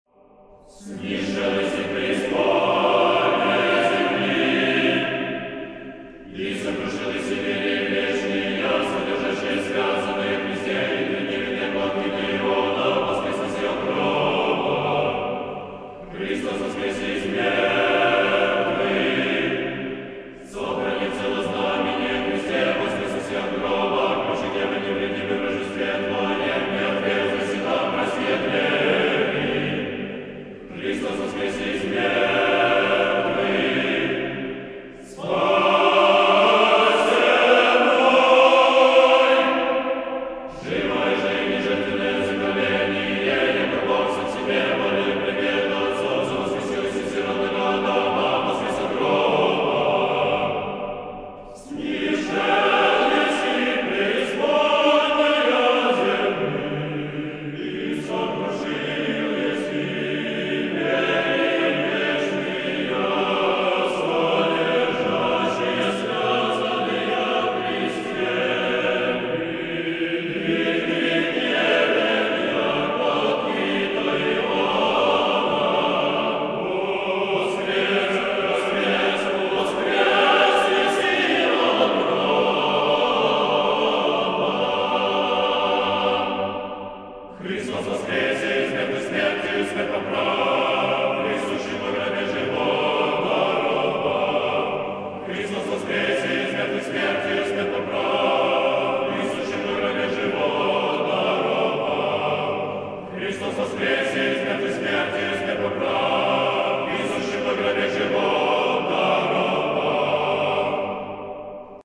Глас 1.